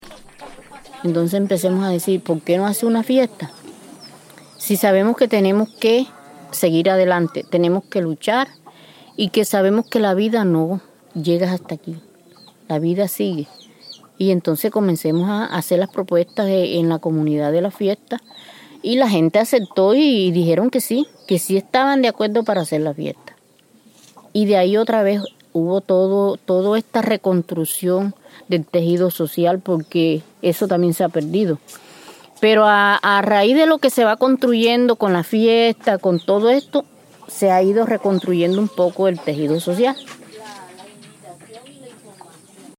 Un recorrido por la memoria que evoca el dolor, la resistencia, la esperanza y las reivindicaciones de las víctimas que ahora alzan su voz frente a la historia que las silenció. Su memoria se narra a partir de sonidos propios del folclore montemariano, como el vallenato y las décimas, y también desde la poesía y los testimonios que interpretan los relatos de los victimarios.